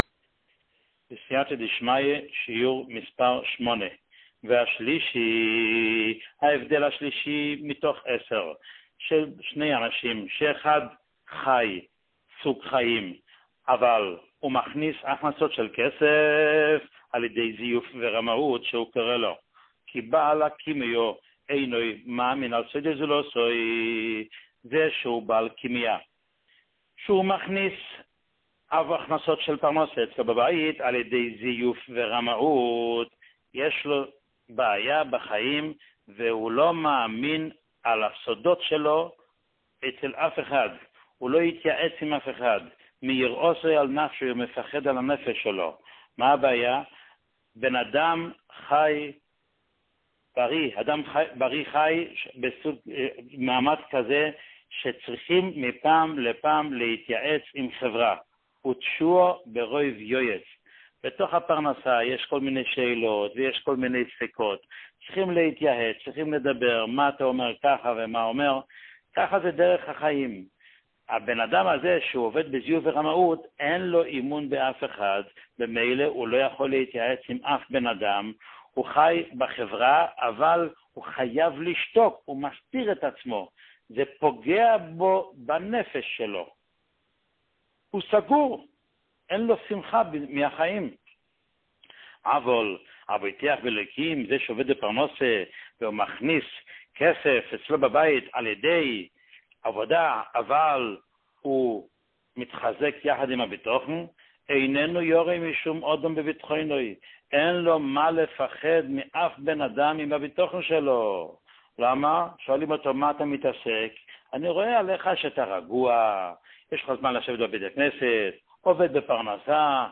שיעורים מיוחדים
שיעור מספר 8